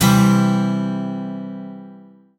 terraria_guitar.wav